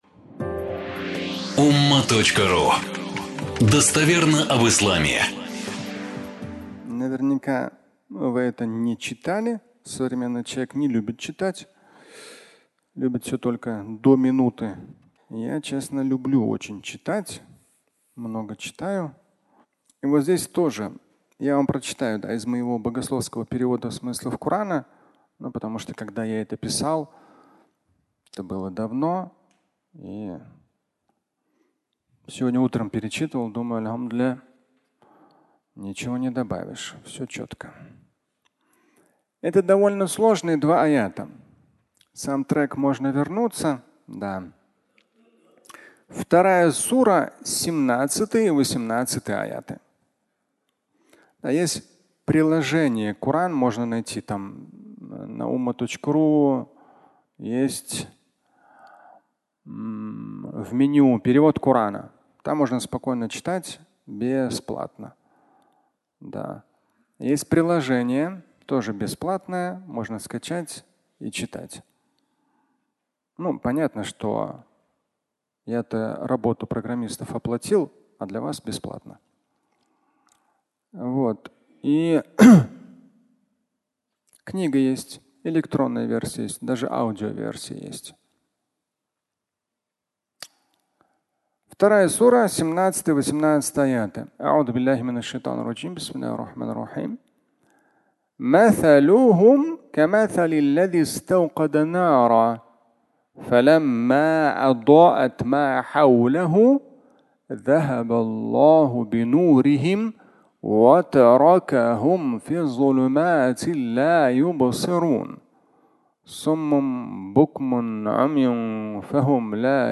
Можно вернуться (аудиолекция)